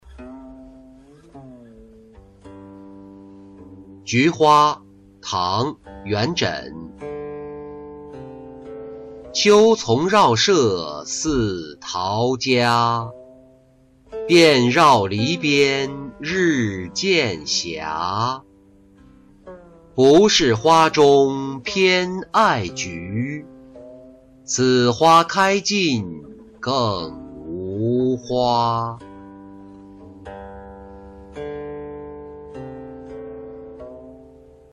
菊花-音频朗读